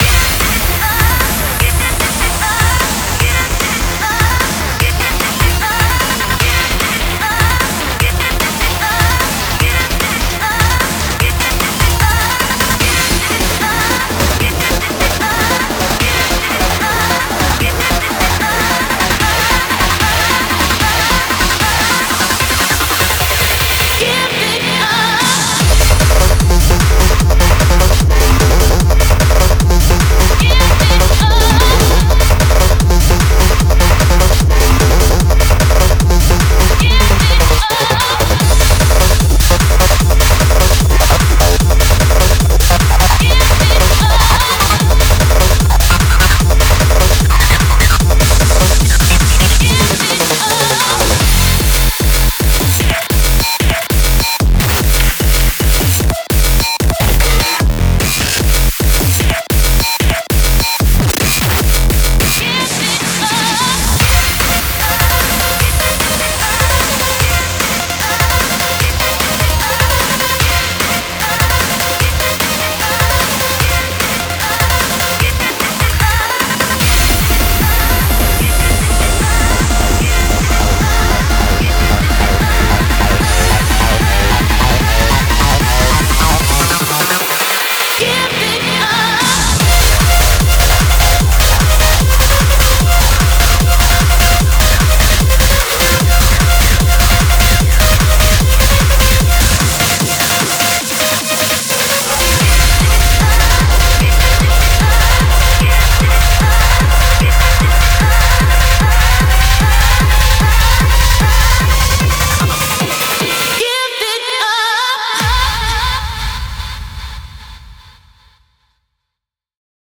BPM150
Audio QualityPerfect (High Quality)
featuring some hard dance beats.